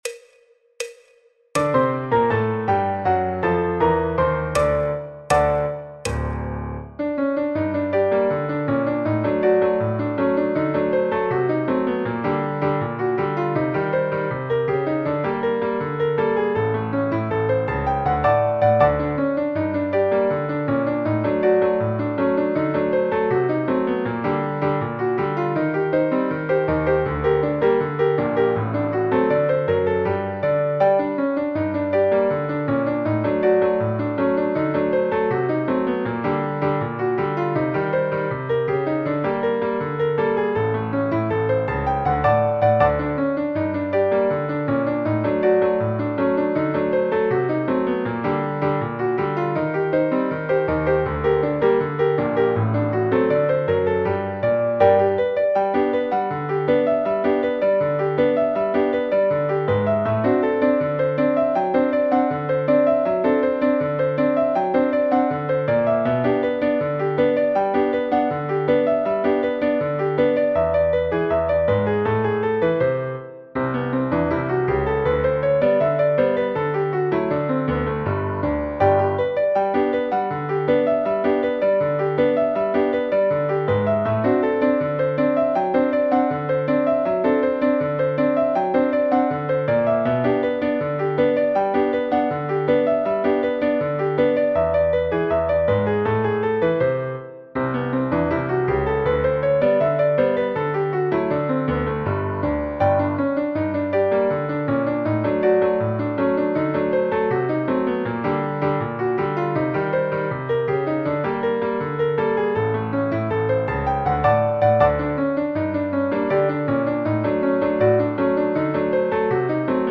Piano Sheet Music in G minor
Choro, Jazz, Popular/Tradicional